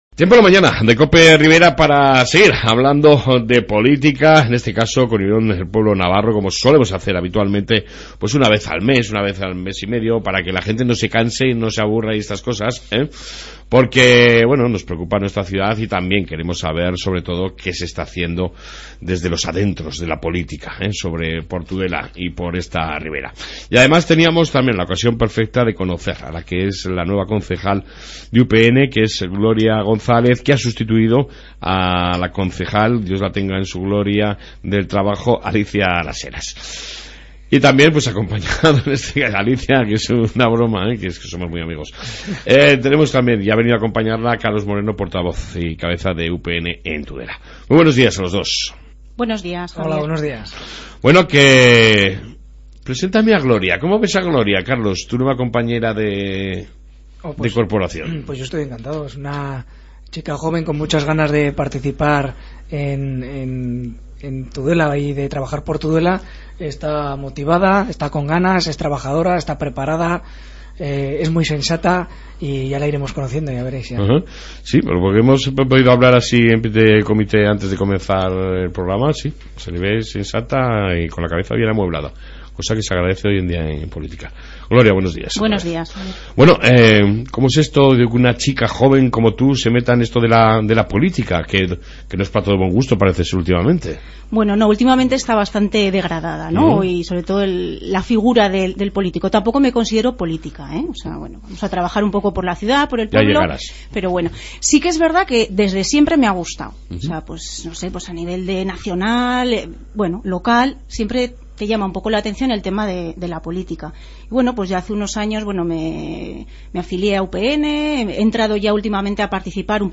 Su visión de la política y otros asuntos de interés, acapararan la entrevista de hoy.